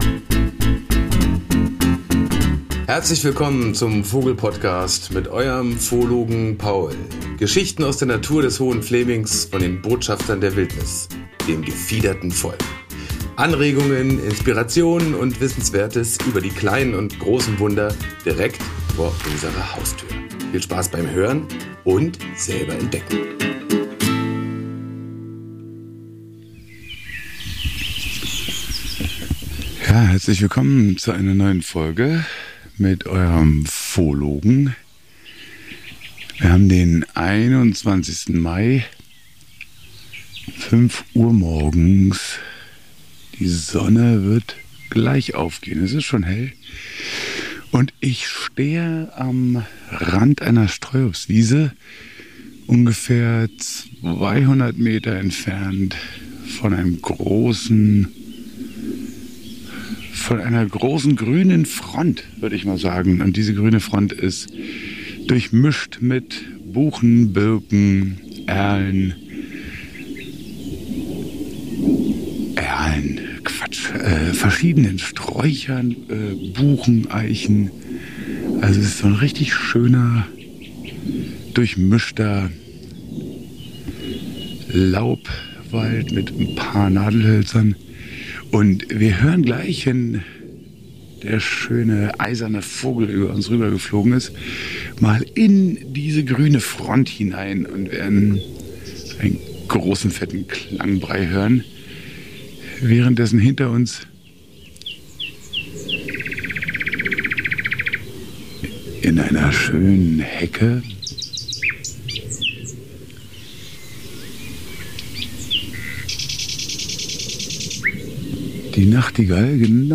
Beschreibung vor 7 Monaten (Empfehlung: Klangbildreise mit Kopfhörern genießen!) In dieser Folge erfährst du Ideen, Tipps und Tricks, um sich Vogelgesänge besser zu merken.